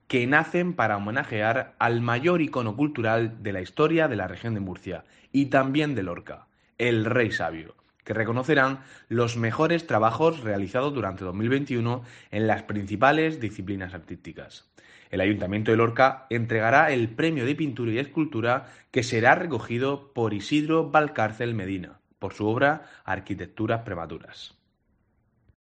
Alfonso Morales, vicealcalde de Lorca